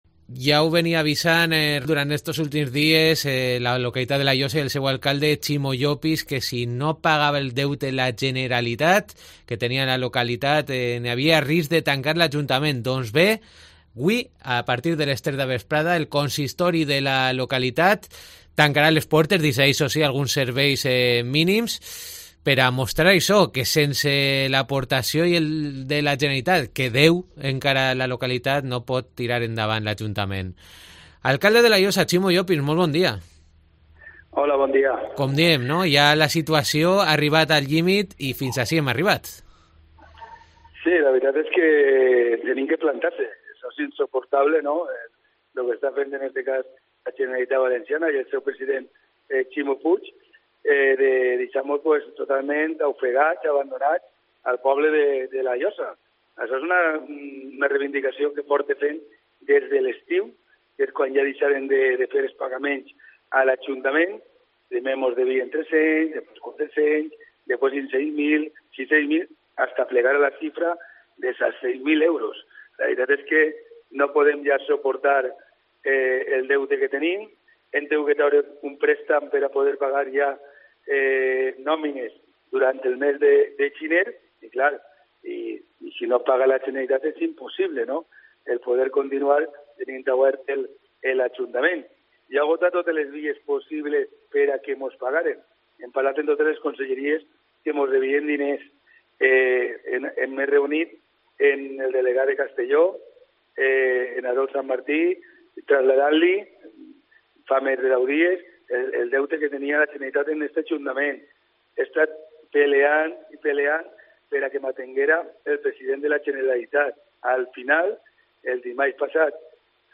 Entrevista a Ximo Llopis, alcalde de La Llosa